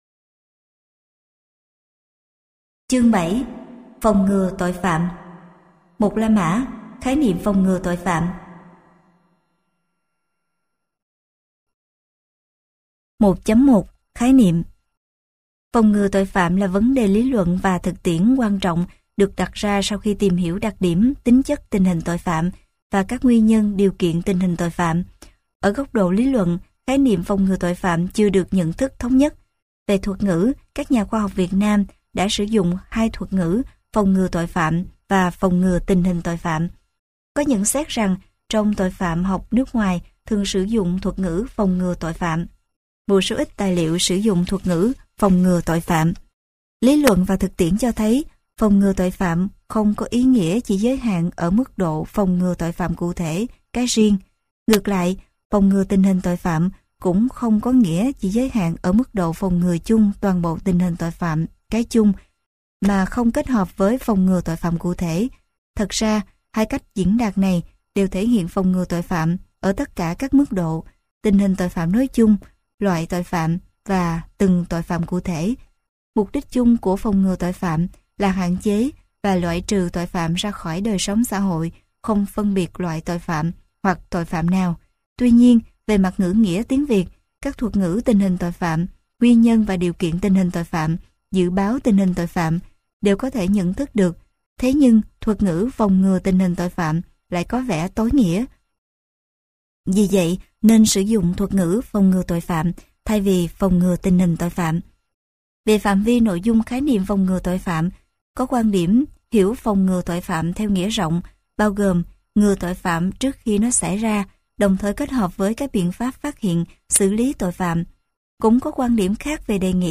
Sách nói Giáo trình Tội phạm học - TS Nguyễn Thị Kim Vân - Sách Nói Online Hay
Giáo trình Tội phạm học Tác giả: TS Nguyễn Thị Kim Vân Nhà xuất bản Hồng Đức Giọng đọc: nhiều người đọc